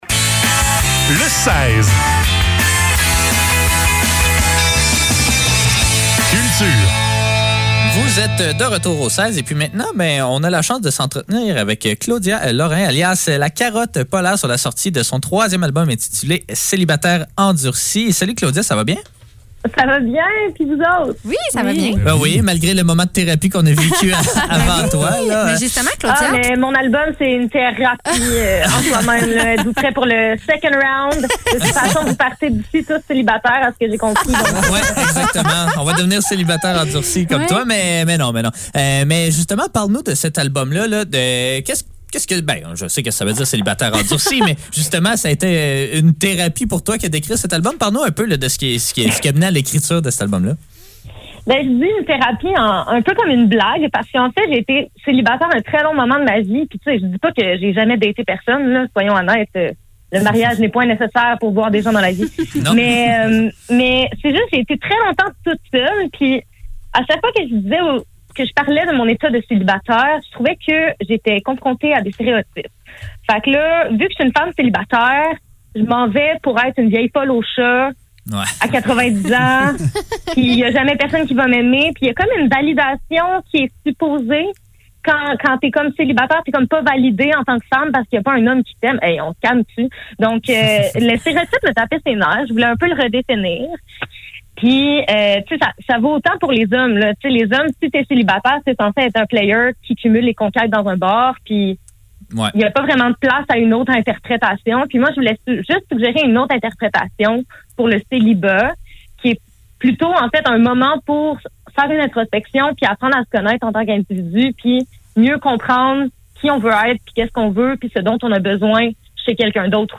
Le seize - Entrevue avec La Carotte Polaire - 8 décembre 2021
Entrevue-avec-La-carotte-polaire.mp3